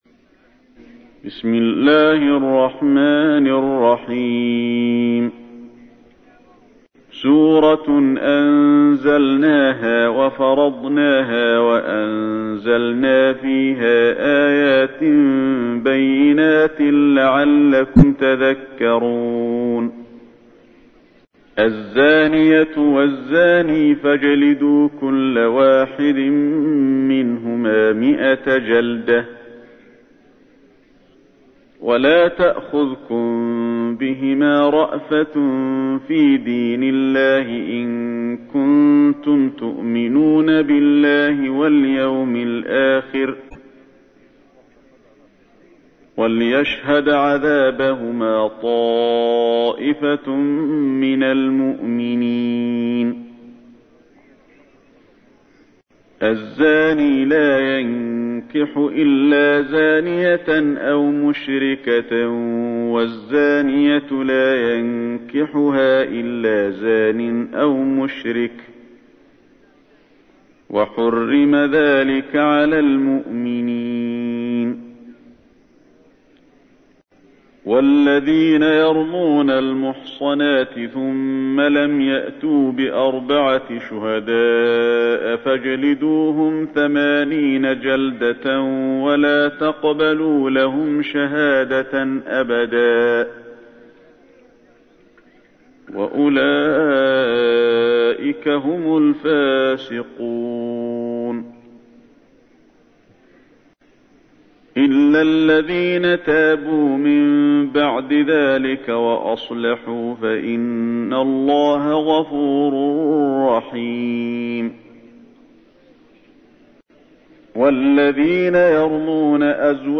تحميل : 24. سورة النور / القارئ علي الحذيفي / القرآن الكريم / موقع يا حسين